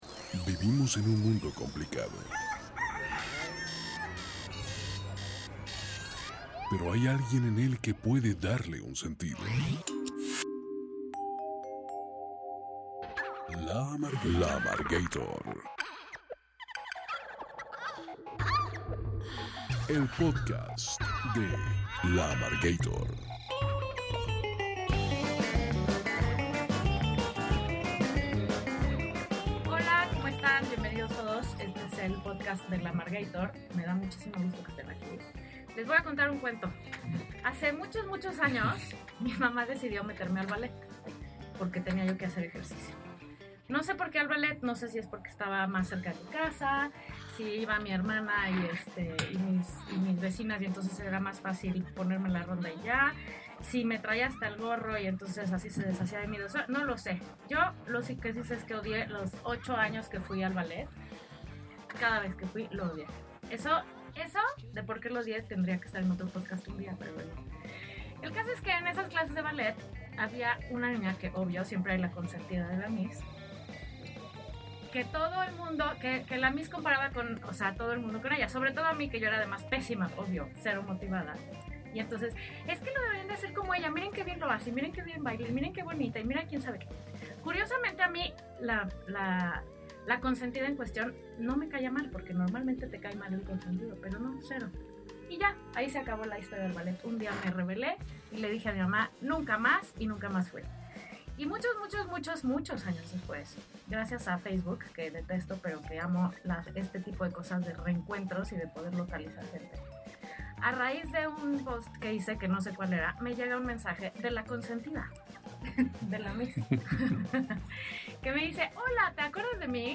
Solicitamos nos disculpen por las fallas de audio, los ocupantes previos de la cabina nos dejaron abierto un micrófono que pescó todos los ruidos de ambiente: el teclado de la laptop, la lluvia sobre el techo del edificio, los ajustes de las sillas, la tos de perro del productor y hasta un par de conversaciones en el recibidor de entrada.